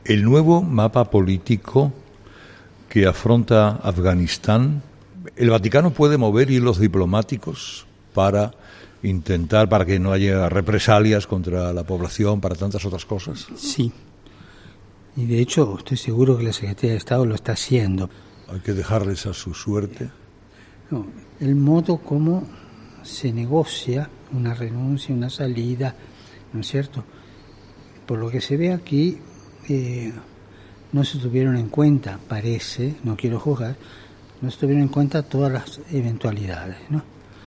Este miércoles a las 8h, la entrevista en exclusiva del Papa Francisco en ‘Herrera en COPE’